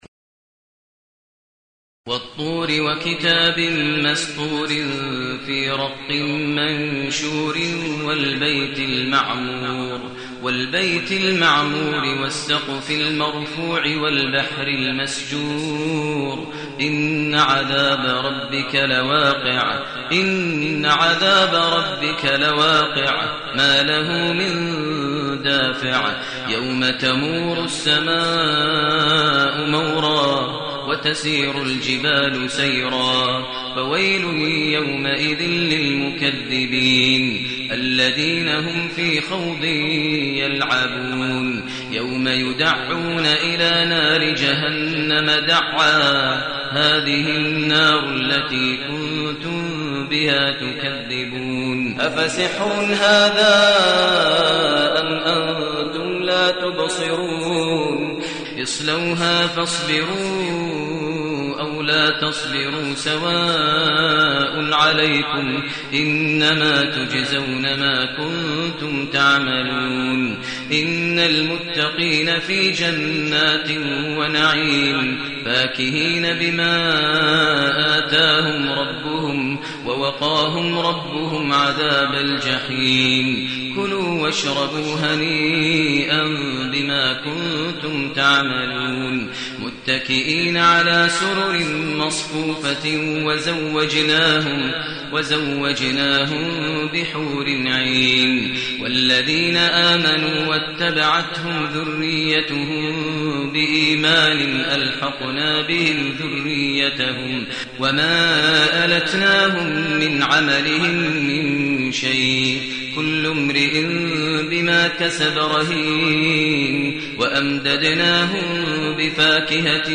المكان: المسجد الحرام الشيخ: فضيلة الشيخ ماهر المعيقلي فضيلة الشيخ ماهر المعيقلي الطور